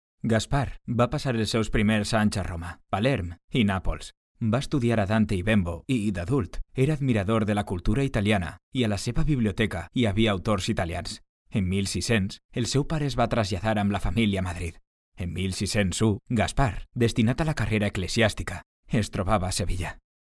Valencian voice over